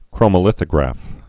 (krōmə-lĭthə-grăf)